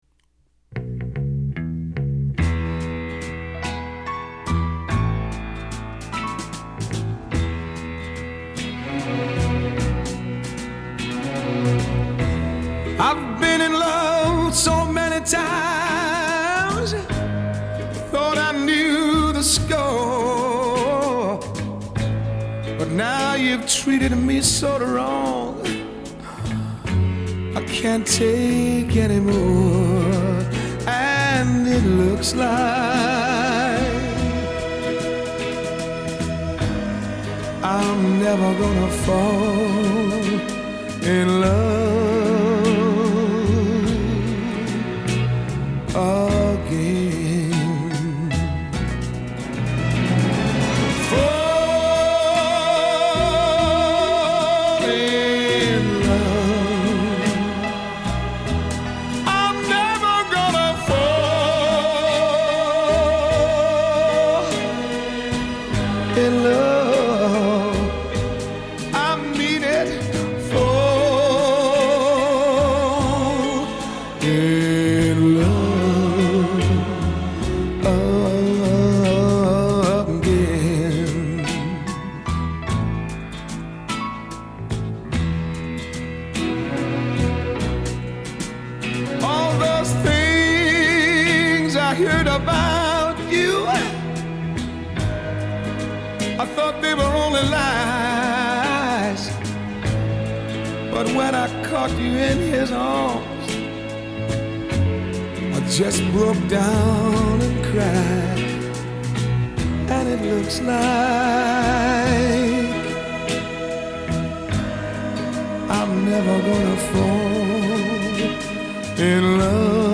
低沉厚重的 Baritone 的聲音